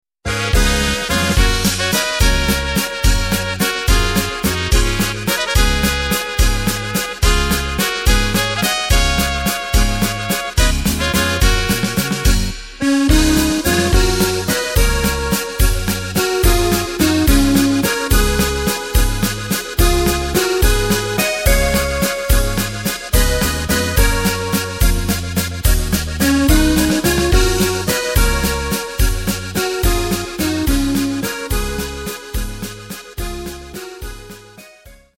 Takt:          3/4
Tempo:         215.00
Tonart:            F#
Walzer Volksm.(Steirisches Volkslied)
Playback mp3 Demo